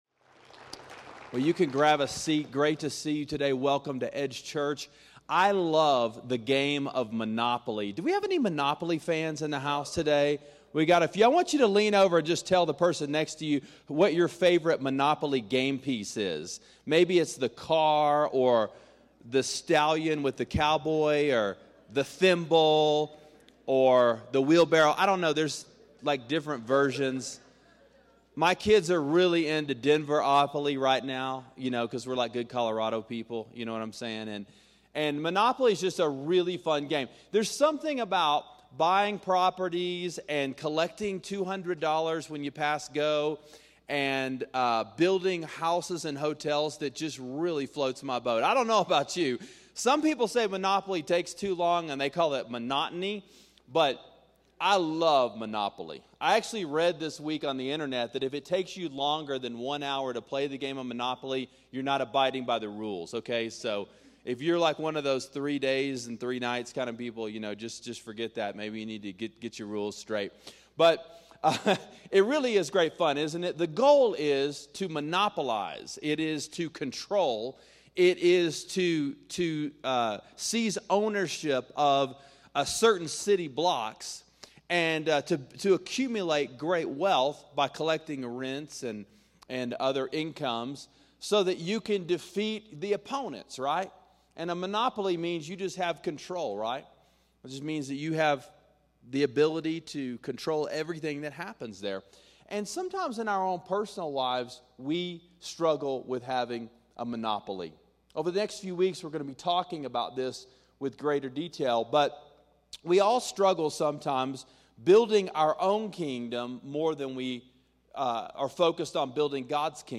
Monopoly: Consumer Or Contributor: Psalm 24:1-2 – Sermon Sidekick